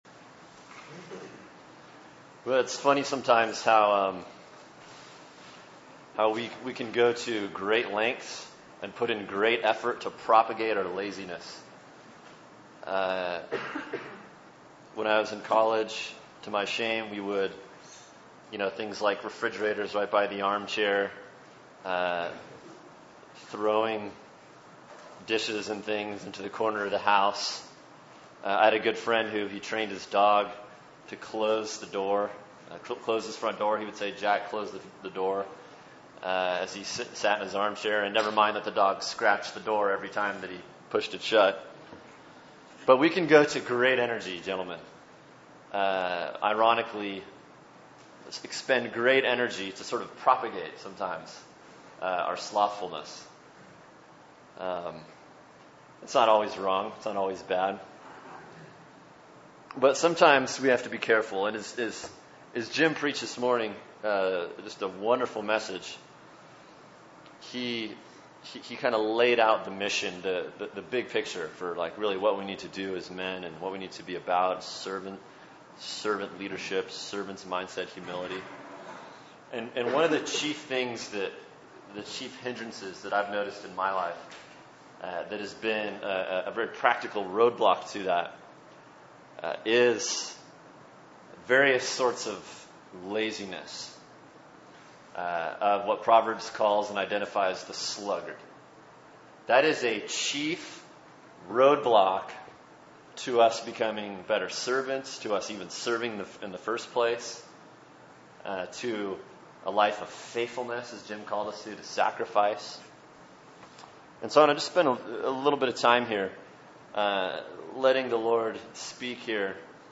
Cornerstone/TVBC Men’s Retreat January 19, 2013